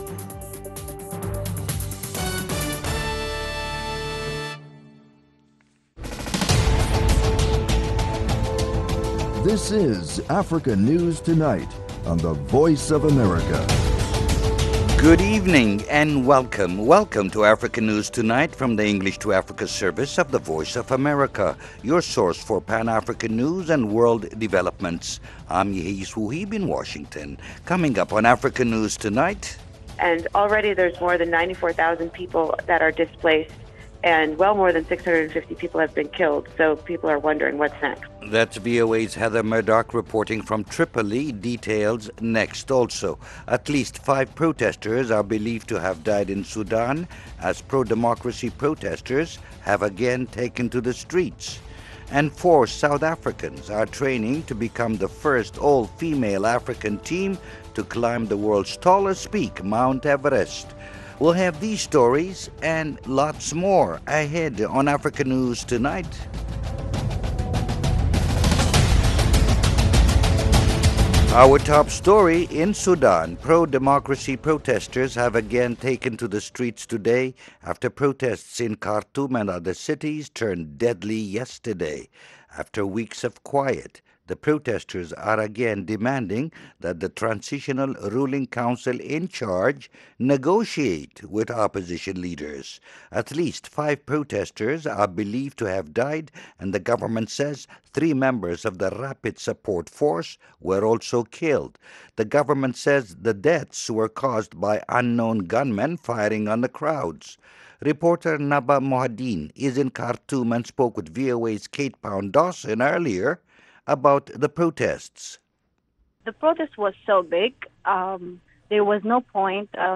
Africa News Tonight is a 30-minute news magazine program that airs twice each evening Monday through Friday, at 1600 and 1800 UTC/GMT. Africa News Tonight brings you the latest news from our correspondents on the continent, as well as interviews with African officials, opposition leaders,...